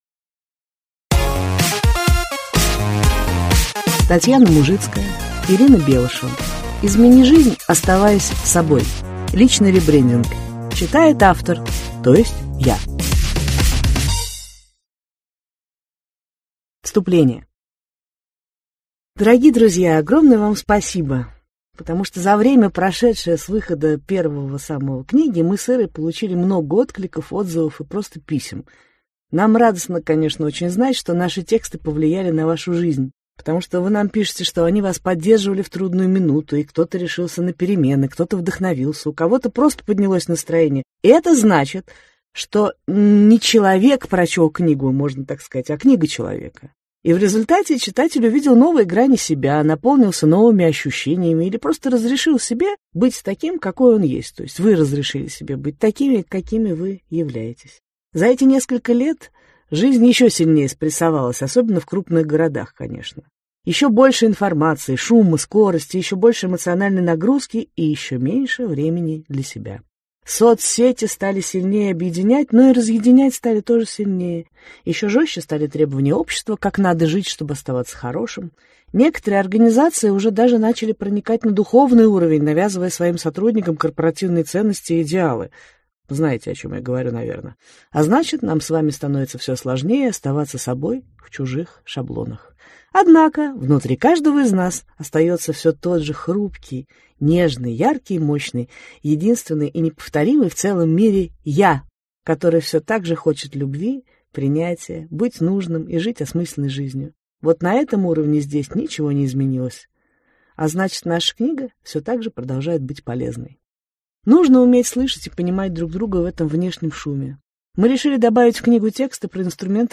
Аудиокнига Измени жизнь, оставаясь собой | Библиотека аудиокниг